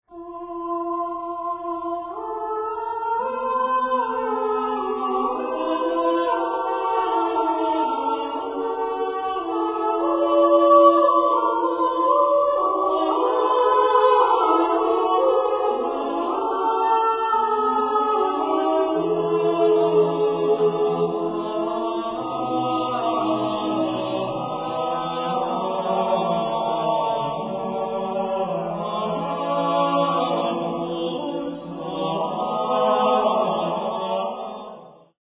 Renaissance Polyphony